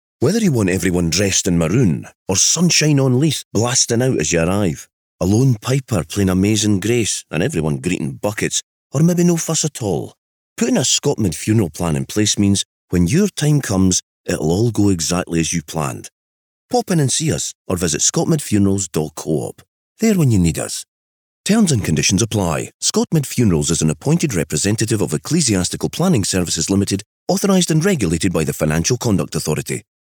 Radio advert
Once the adverts had been approved, the recording studio Finiflex in Leith was booked and the two adverts were recorded.